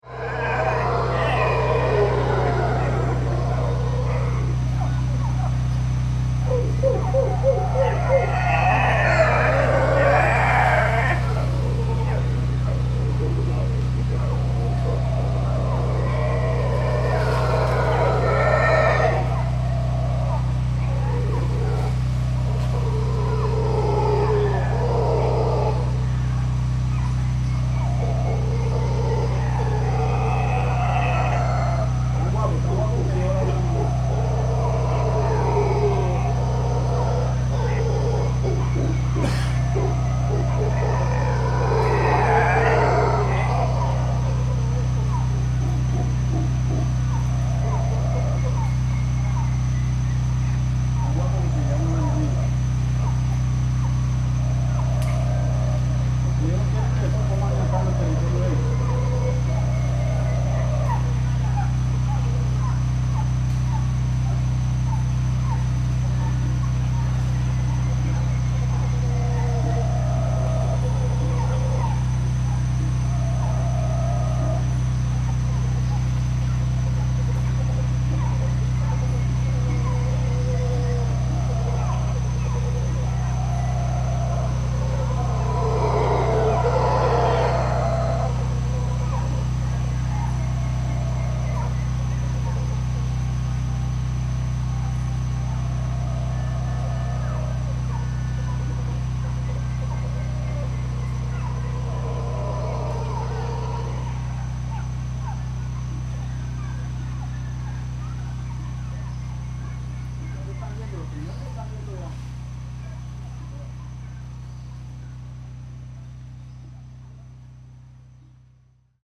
Roar of the wild, hum of the machine
In the Chocó jungle near Capurganá, Colombia, the natural soundscape is interrupted by the hum of generators. This recording captures the morning interplay between the haunting calls of howler monkeys and the mechanical drone of generators, creating a unique, chaotic harmony.